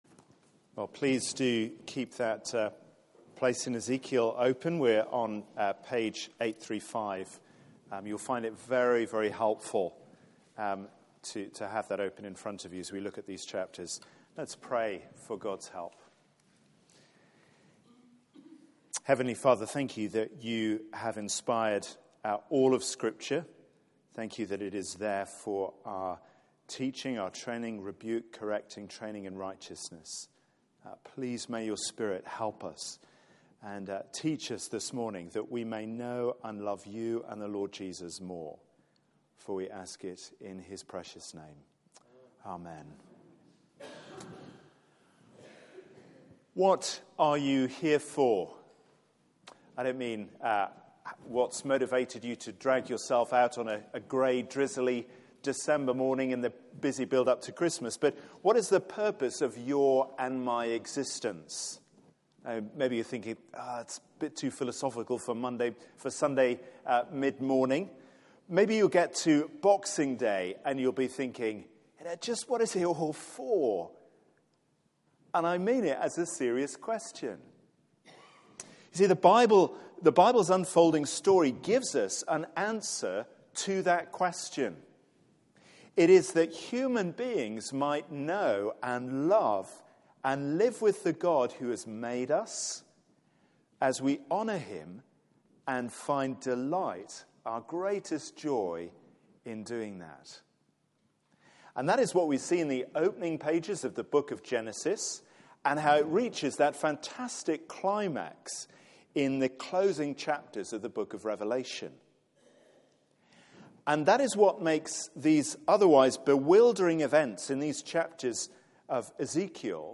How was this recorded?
Media for 9:15am Service on Sun 13th Dec 2015 09:15 Speaker